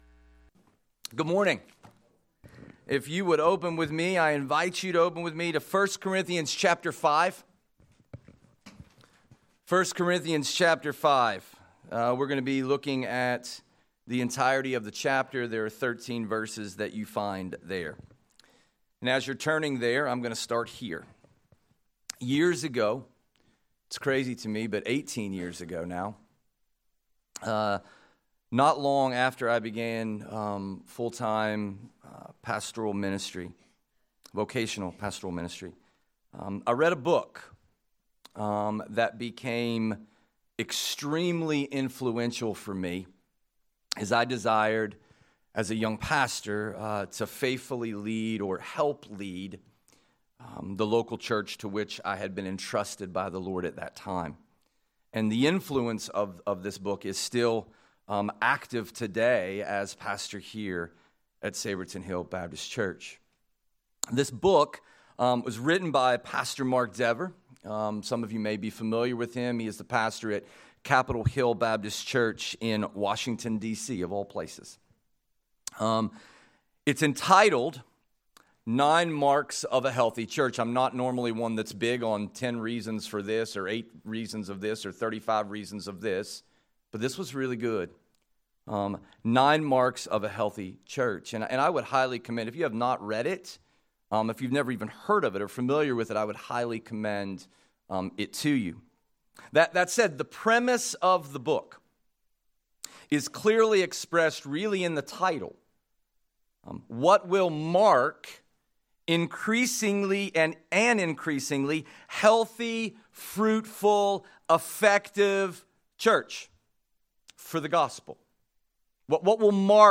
Jul 27, 2025 The Grace of Church Discipline (07/27/2025) MP3 SUBSCRIBE on iTunes(Podcast) Notes Discussion Sermons in this Series 1 Corinthians 5:1-13 Loading Discusson...